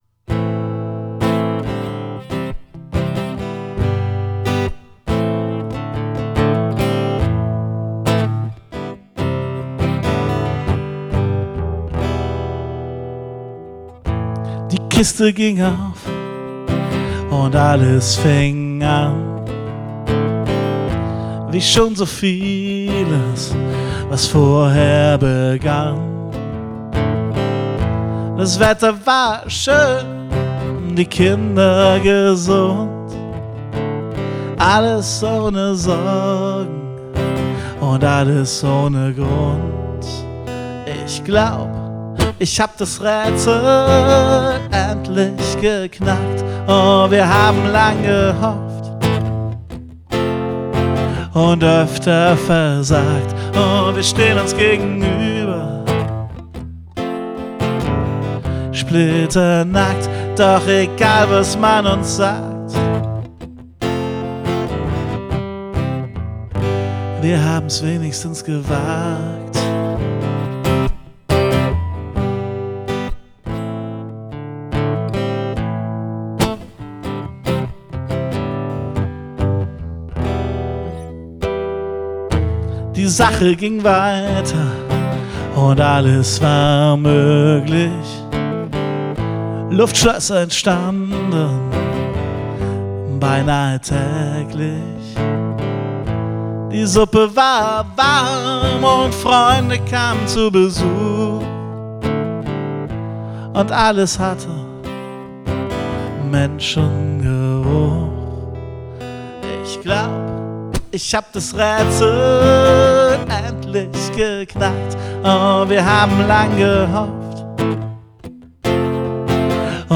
Aufgenommen und gemischt am 29. Juli 2017